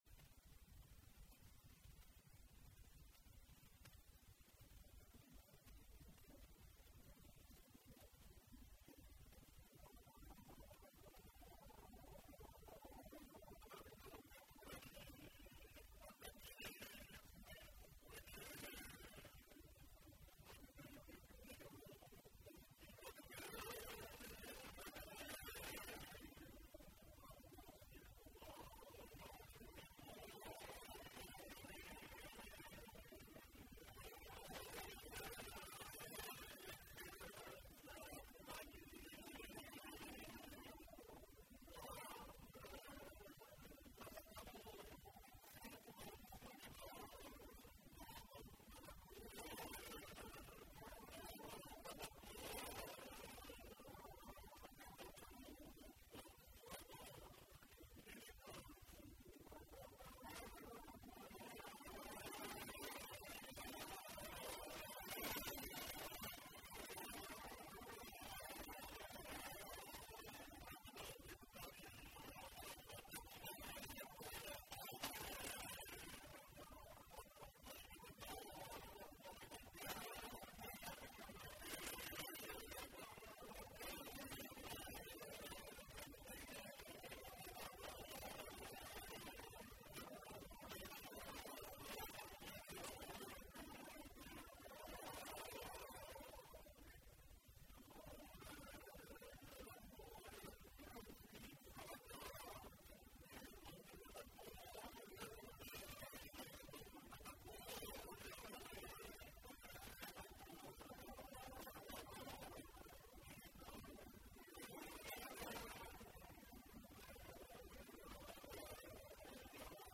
Описание: Качество приемлемое